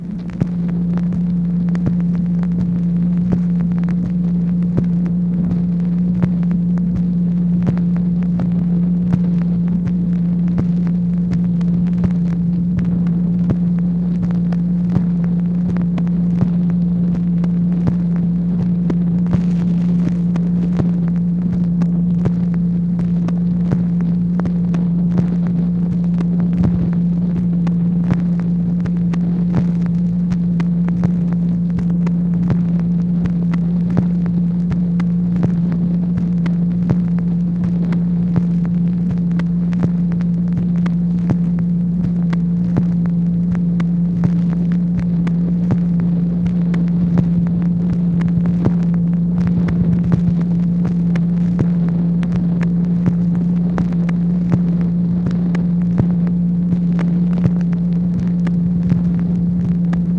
MACHINE NOISE, July 30, 1964
Secret White House Tapes | Lyndon B. Johnson Presidency